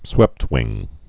(swĕptwĭng)